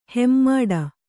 ♪ hemmāḍa